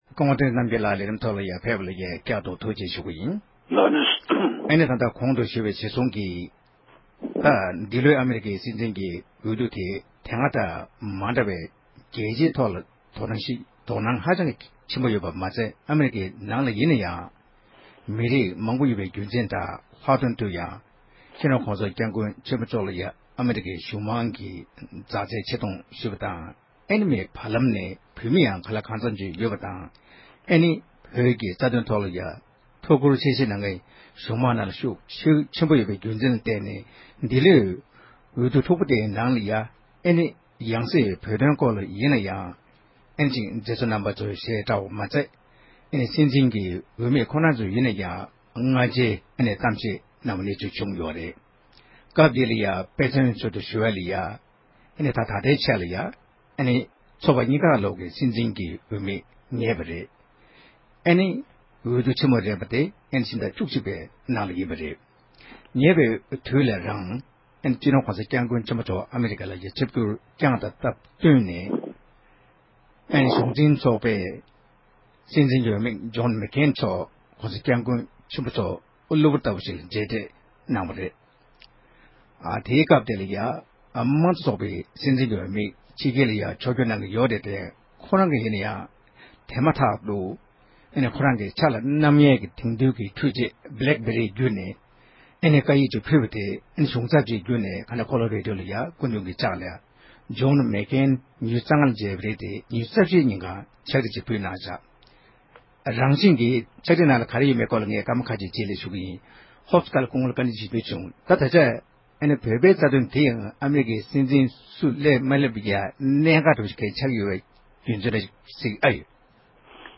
བལ་ཡུལ་གྱི་གྲོས་ཚོགས་ནང་མཀེསེ་ལེ་ནིན་ཆིག་སྒྲིག་ཚོགས་པའི་འཐུས་མི་མཁན་པོ་འཆི་མེད་ཚེ་རིང་ལགས་དང་གླེང་བ།